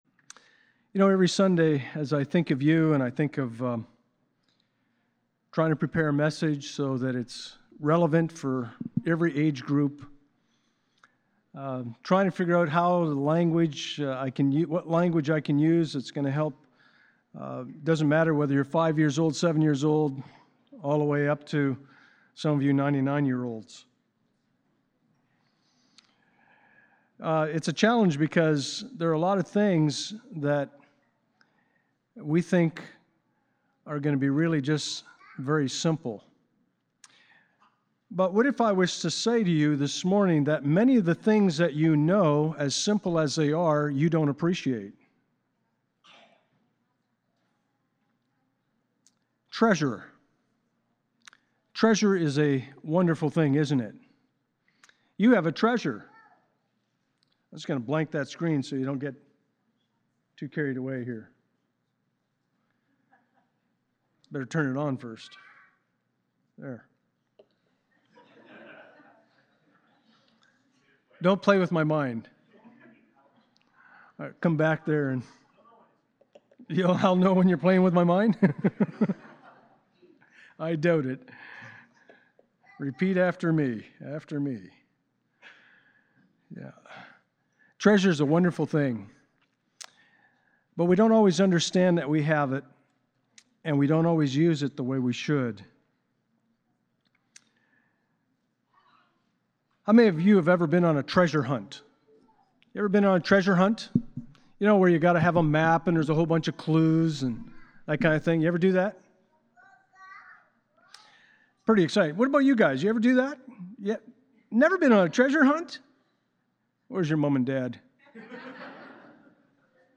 2 Corinthians Passage: 2 Corinthians 4:1-7 Service Type: Sunday Morning « Cherishing the Moment